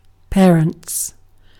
Ääntäminen
IPA : /ˈpɛɹ.ənts/ IPA : /ˈpæɹənts/